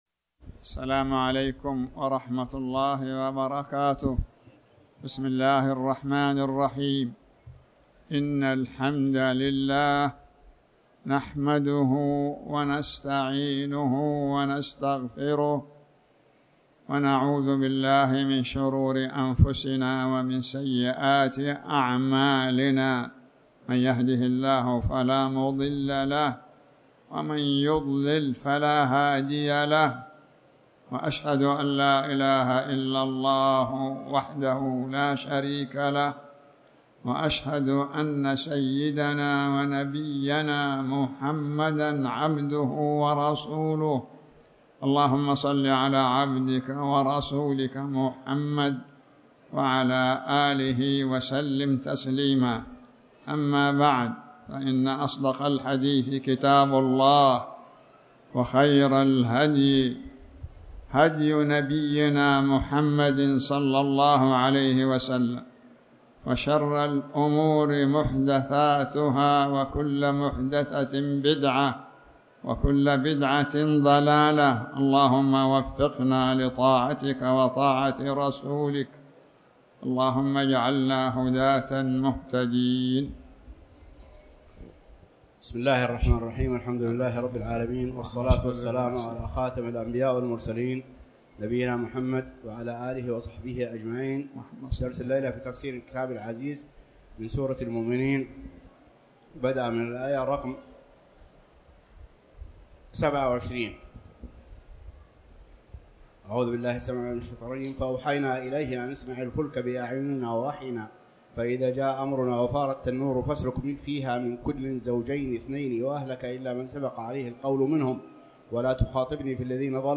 تاريخ النشر ٢٠ شوال ١٤٣٩ هـ المكان: المسجد الحرام الشيخ